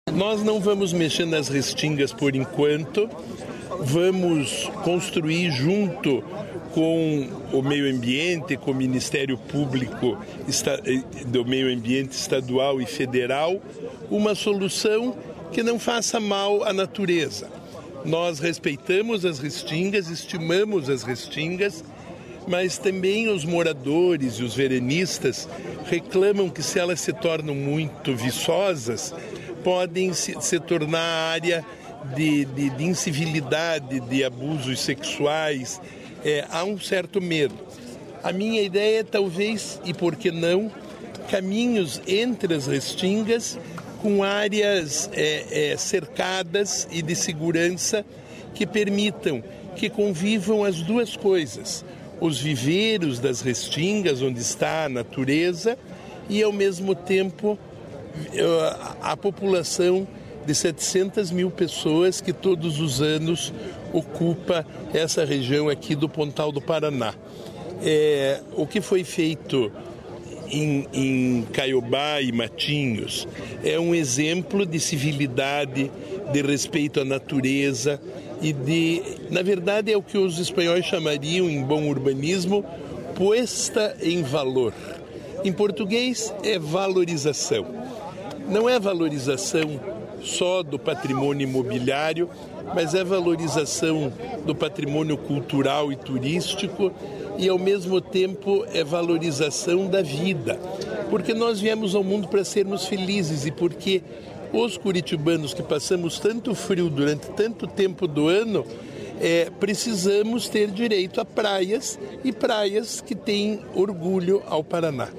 Sonora do secretário estadual do Desenvolvimento Sustentável, Rafael Greca, sobre requalificação da orla de Pontal do Paraná